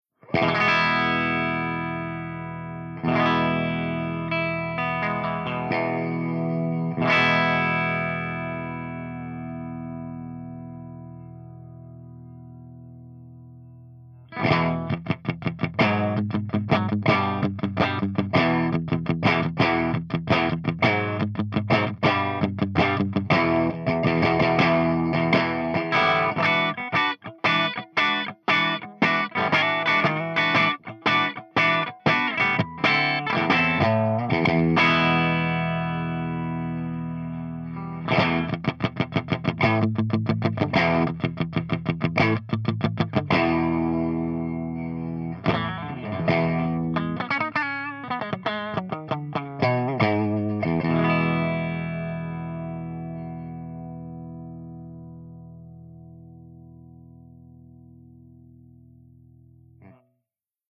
154_EVH5150_CH1CLEAN_V30_HB